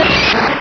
Cri d'Amonita dans Pokémon Rubis et Saphir.
Cri_0138_RS.ogg